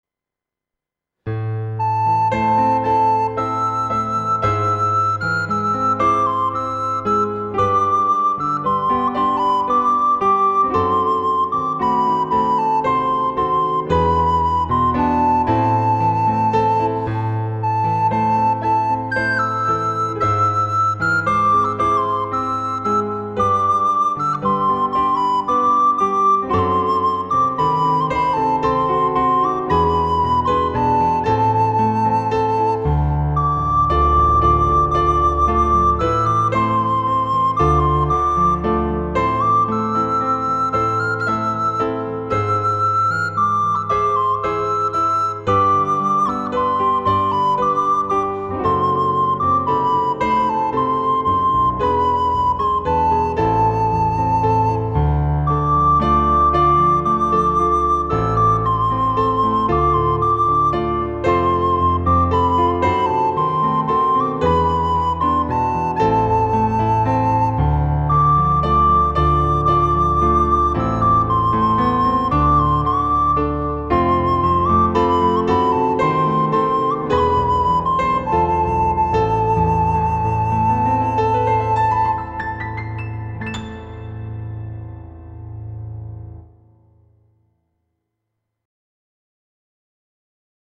راهنمای کامل برای اجرای ملودی آذربایجانی دلنشین
ایرانی